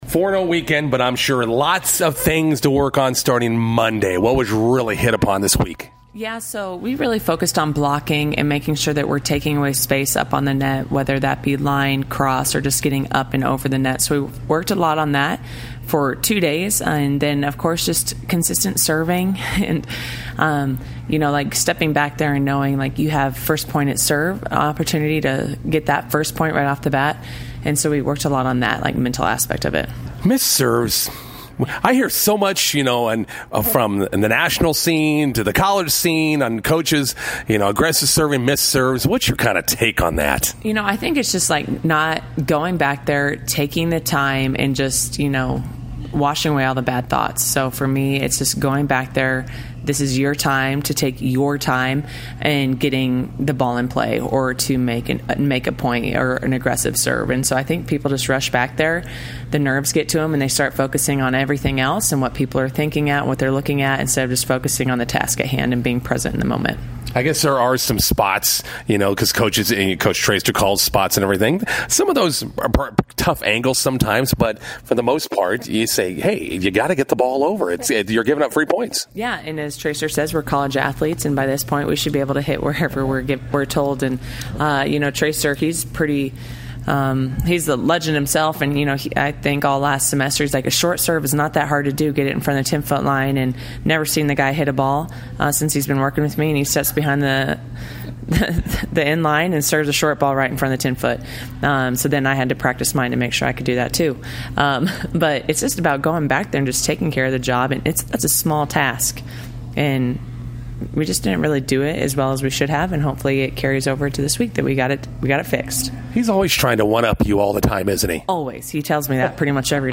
INTERVIEW: McCook Community College volleyball hosting a 13-team tournament starting today at the Graff Events Center.